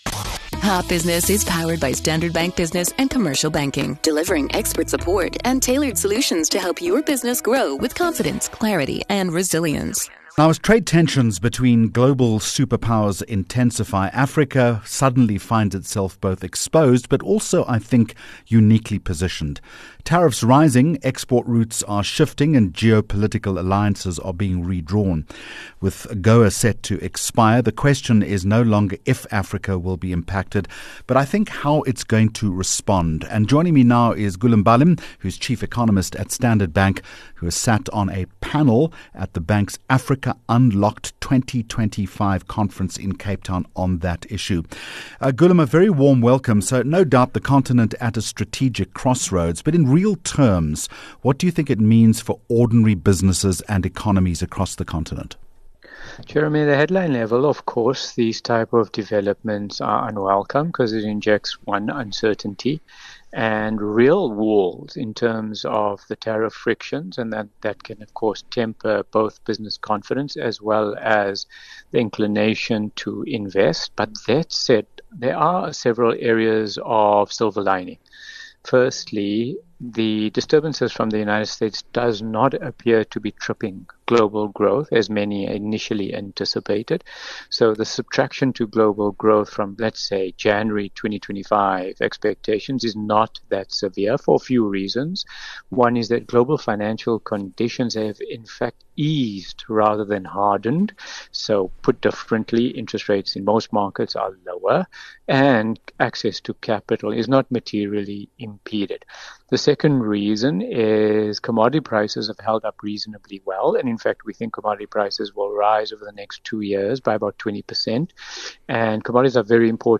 10 Jul Hot Business Interview